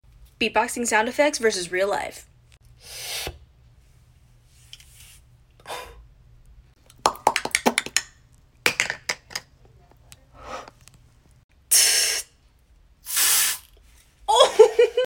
Beatbox sound effects 🗣 sound effects free download